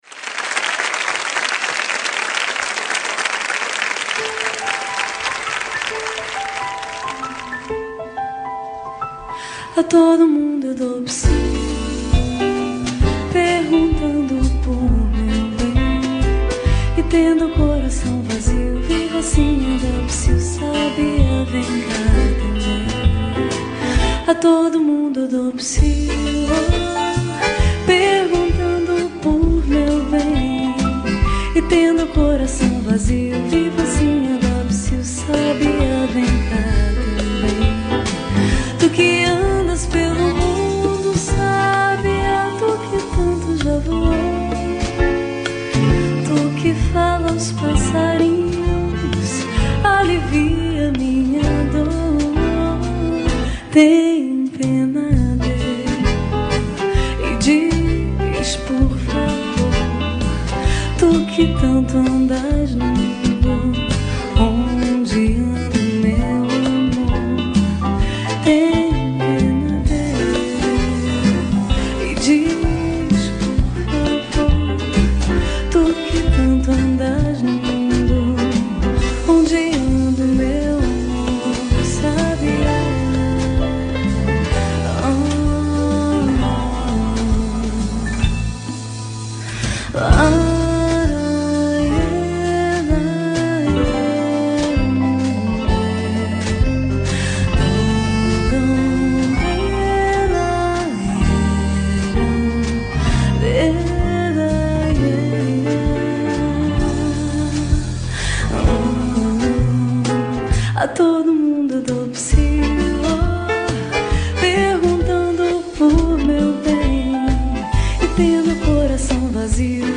Ao Vivo
Arranjos e Piano
Bateria
Baixo
Percuss?o
Guitarra
sanfona